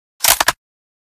reload_end.ogg